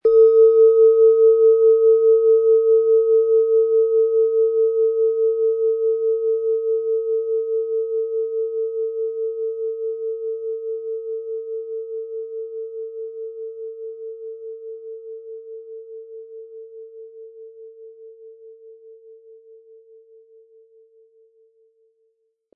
Planetenton 1
Die Klangschale wurde nach alter Tradition von Hand getrieben.
Im Sound-Player - Jetzt reinhören hören Sie den Original-Ton dieser Schale. Wir haben versucht den Ton so authentisch wie machbar hörbar zu machen, damit Sie hören können, wie die Klangschale bei Ihnen klingen wird.
Den passenden Schlegel erhalten Sie kostenfrei mitgeliefert, der Schlägel lässt die Schale voll und wohltuend erklingen.